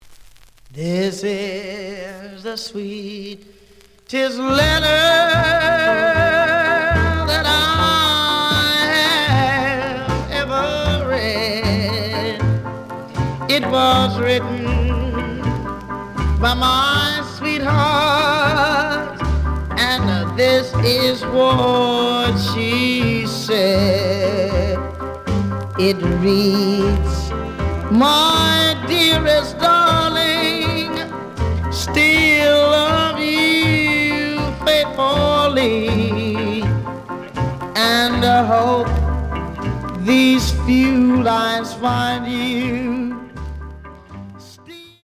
試聴は実際のレコードから録音しています。
●Genre: Rhythm And Blues / Rock 'n' Roll
A面の最後とB面の前半に傷によるノイズがあるが、全体的にはおおむね良好。)